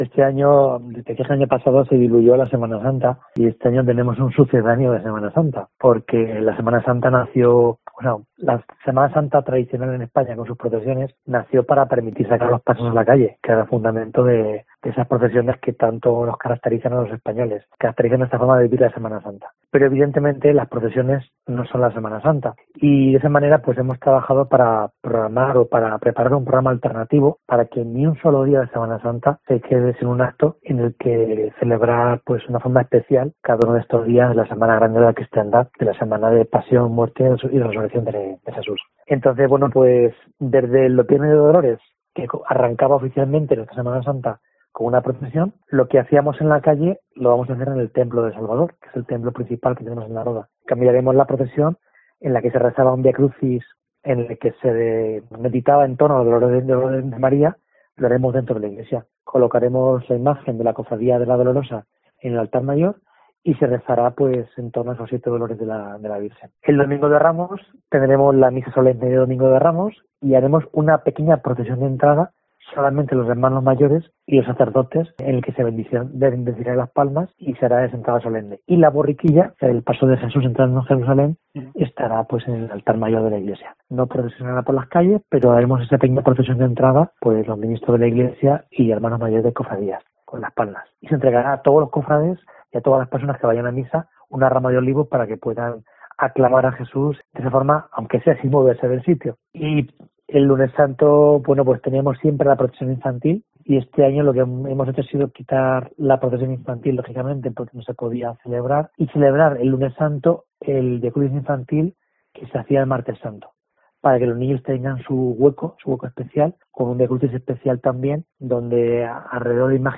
(Entrevista)